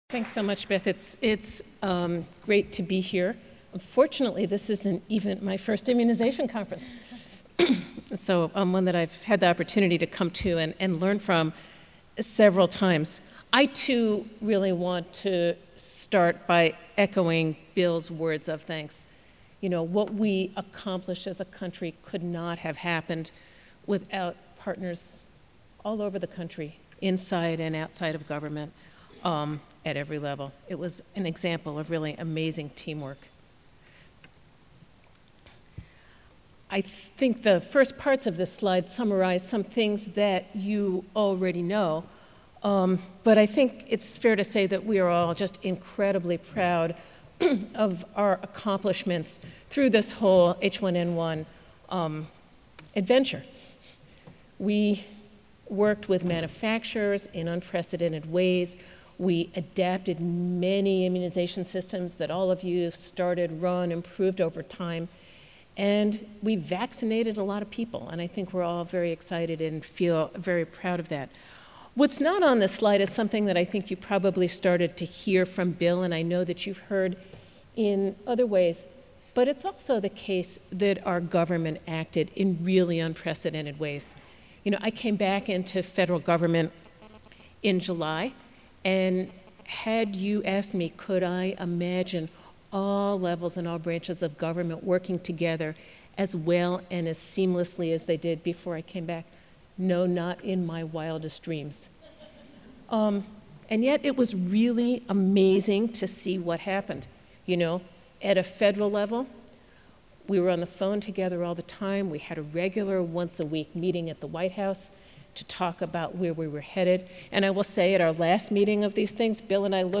Centennial Ballroom II/III/IV Nicole Lurie, MD , Assistant Secretary for Preparedness and Response, US Department of Health and Human Services
Recorded presentation